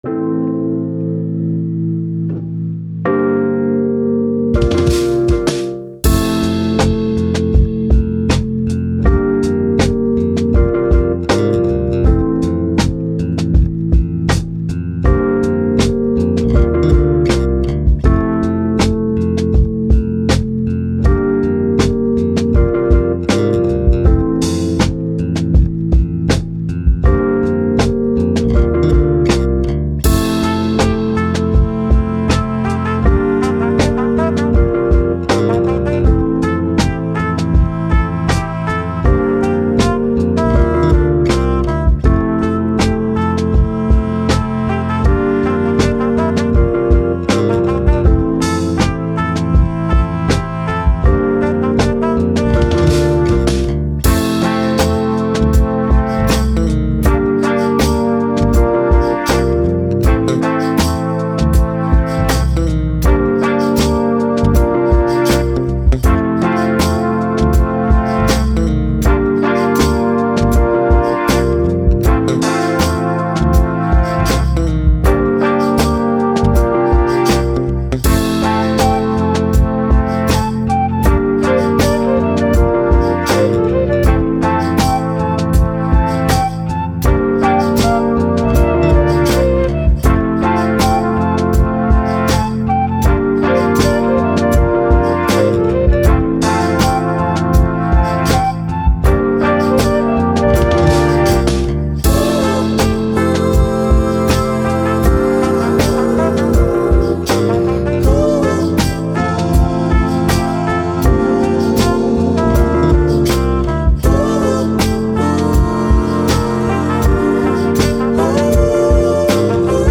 Soul, Hip Hop, Positive, Sun, Vibe, Vintage, Chill